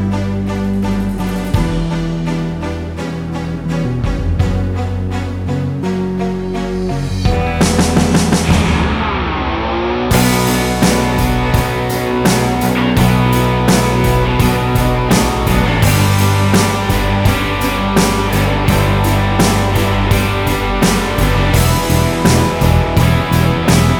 Minus Solo Guitars Rock 4:03 Buy £1.50